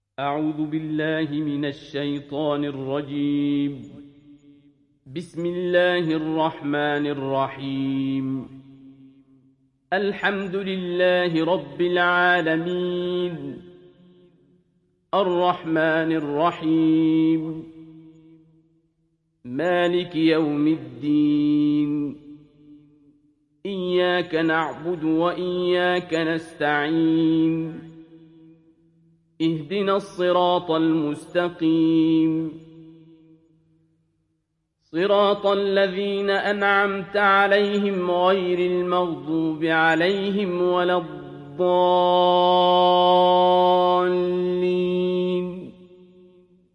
تحميل سورة الفاتحة mp3 بصوت عبد الباسط عبد الصمد برواية حفص عن عاصم, تحميل استماع القرآن الكريم على الجوال mp3 كاملا بروابط مباشرة وسريعة